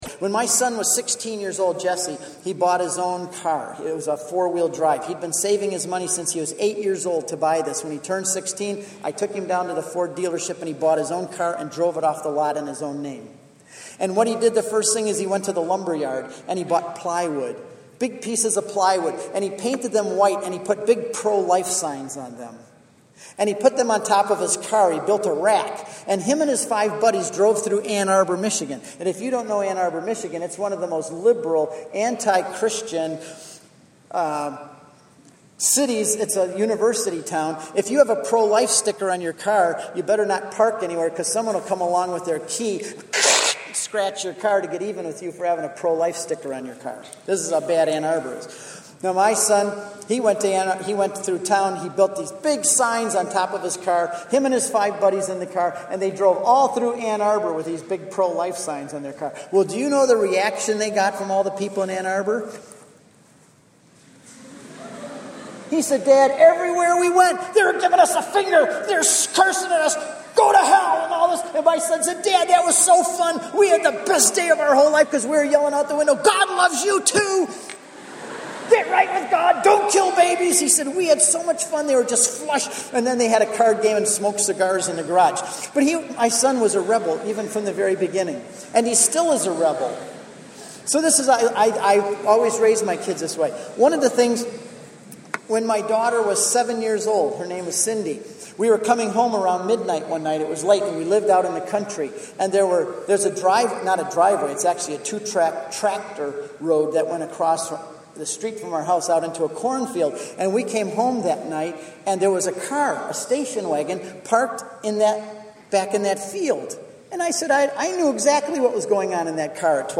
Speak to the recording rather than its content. I was asked to speak for an hour to a group of 800 teens in Peoria Illinois.